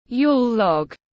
Bánh kem hình khúc cây tiếng anh gọi là yule log, phiên âm tiếng anh đọc là /ˈjuːl ˌlɒɡ/